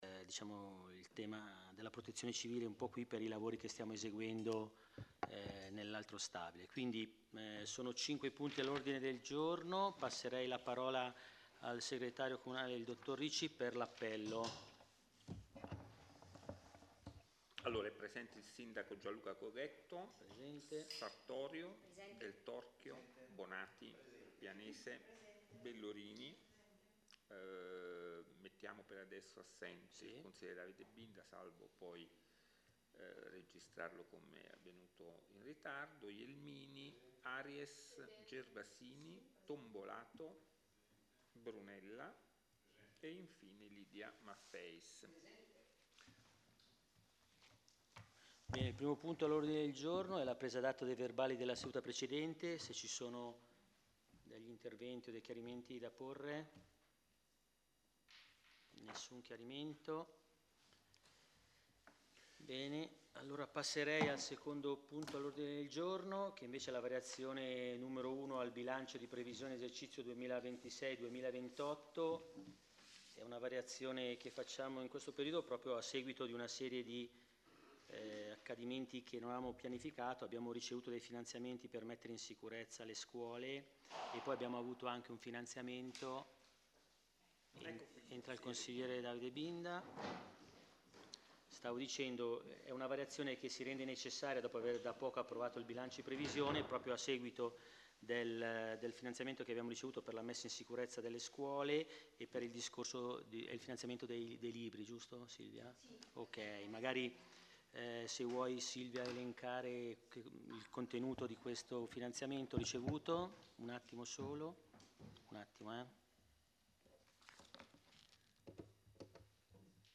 Registrazione Consiglio Comunale del 09.02.2026